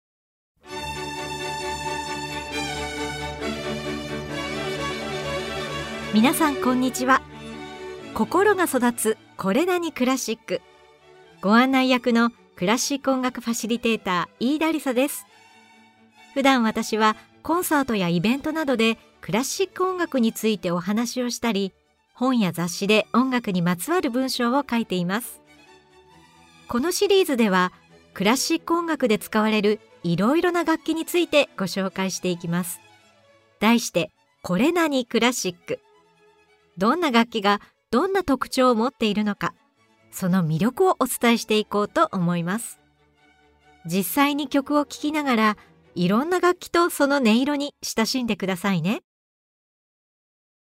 実際に曲を聴きながら、いろんな楽器とその音色に親しんでください。
Vol.2では、前回に続き「ピアノ」に注目！ピアノ工房の職人たちにアドバイスしながら曲を作ったベートーヴェン、ピアニストで作曲家のショパン、ピアニストになりたかった経歴を持つシューマン、生まれたばかりの娘のために曲を作ったドビュッシー、ピアニストとしても大成功したラフマニノフなどのピアノ楽曲を紹介しながら、ピアノのさまざまな音色とその魅力を紹介します！